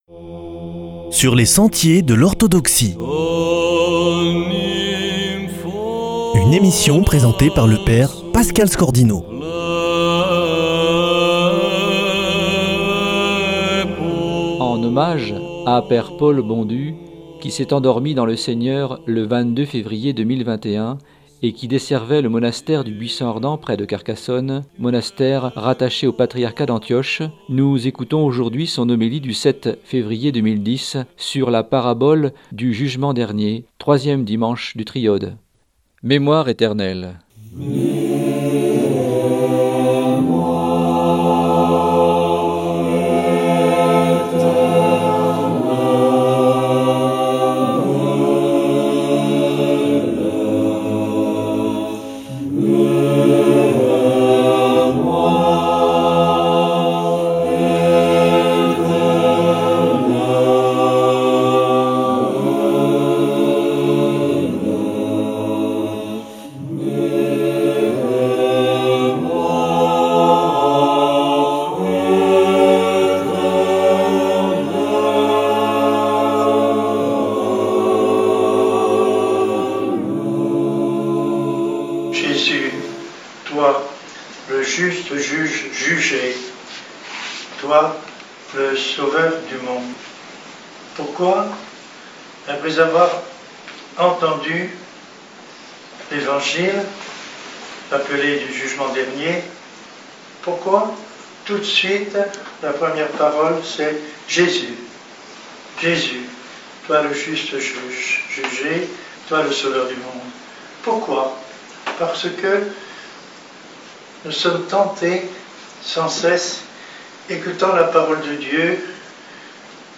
homélie 2010 sur la parabole du Jugement Dernier